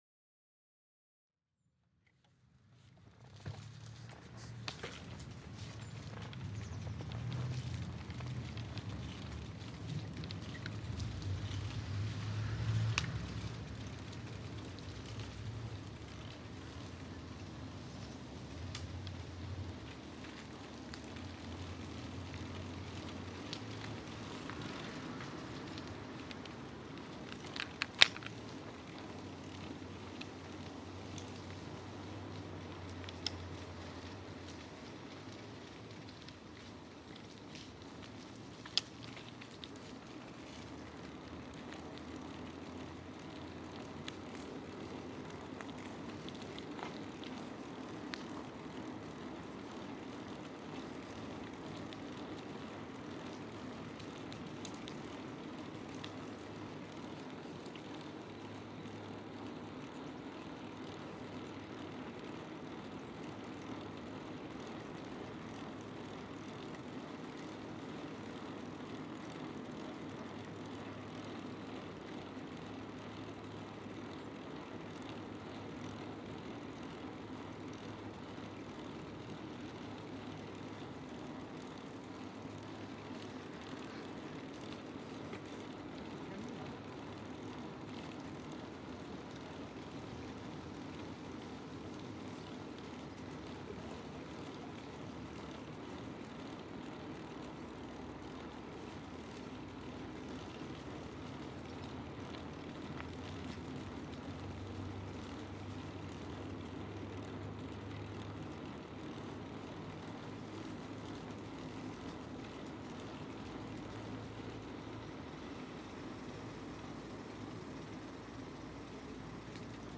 Means of transport: Touring city bike, 7-speed Distance covered: approx. 2800 meters Weather: light wind, sunny Use headphones to benefit from the binaural effect.